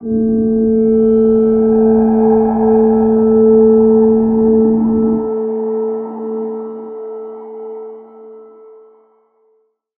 G_Crystal-A4-pp.wav